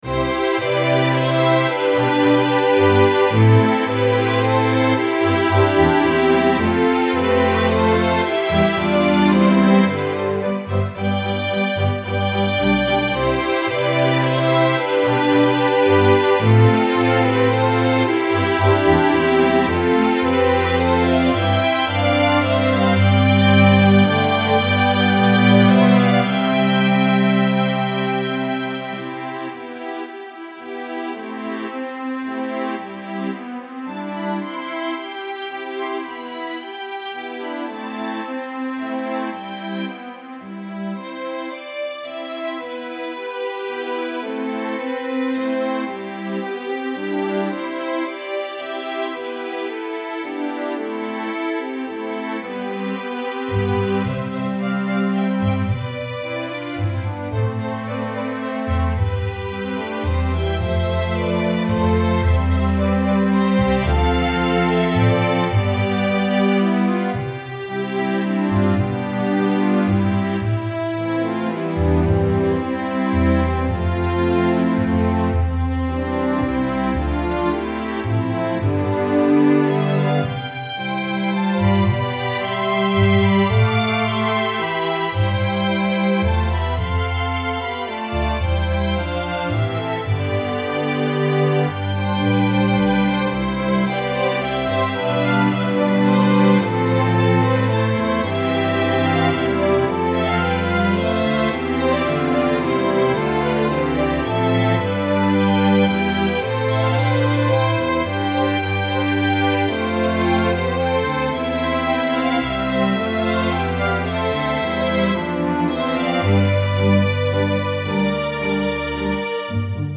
Instrumentation: Violin 1 2, Viola, Cello, Double Bass